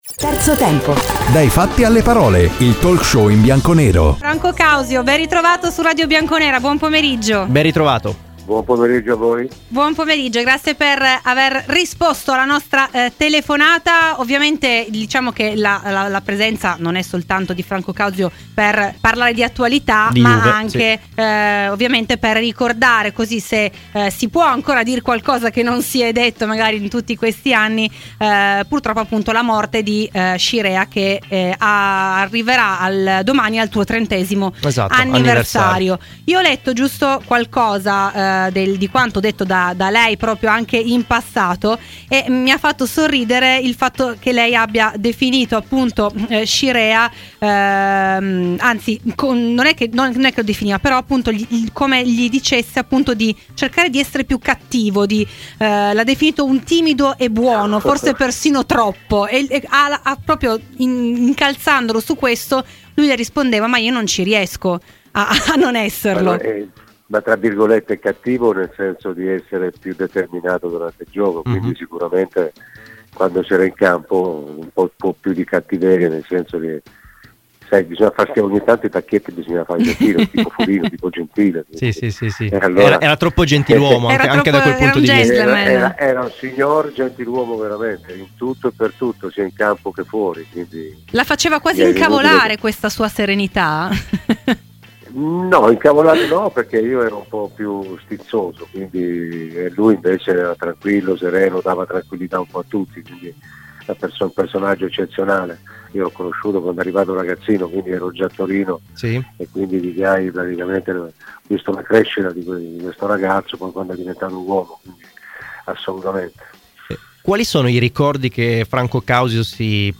ai microfoni di "Terzo tempo"